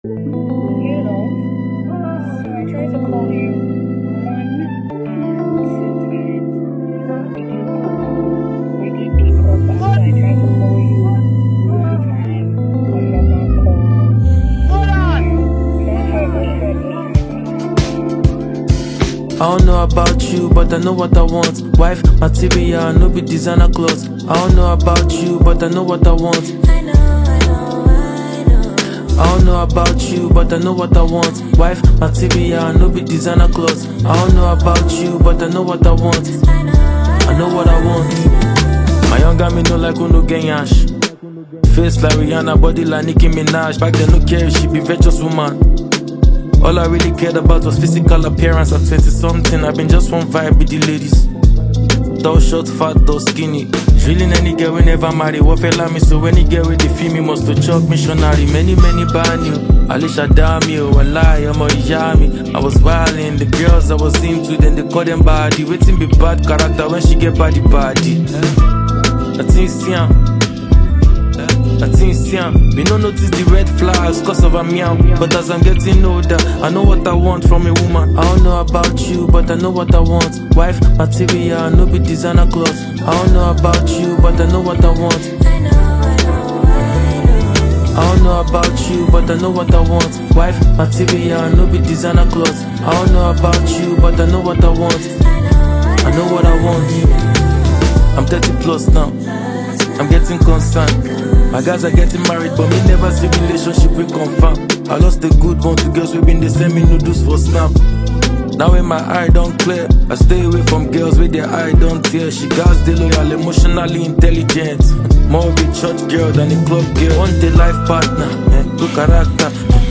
Nigerian Afro‑Hip‑Pop single
smooth blend of melodic rap and Afro‑inspired rhythms
With playful lyrical wordplay and contemporary production
dynamic vocal flow and emotive storytelling